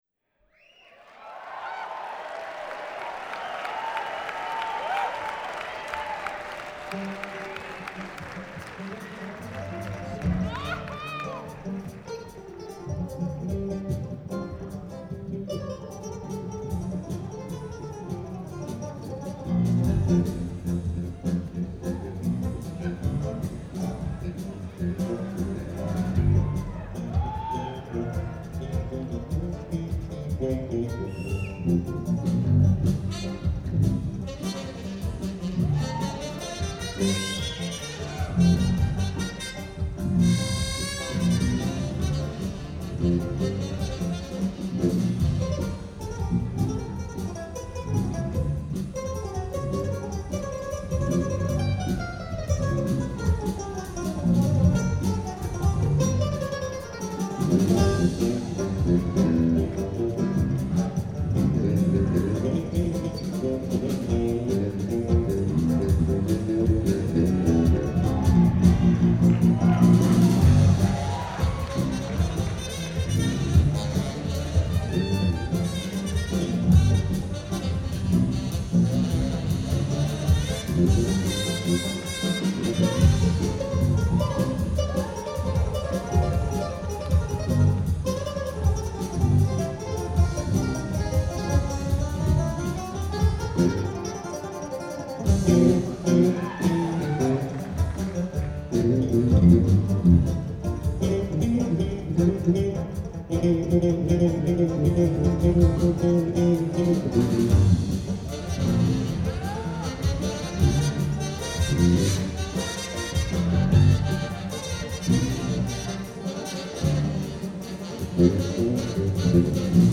February 10, 2007 – Paramount Theater, Denver, CO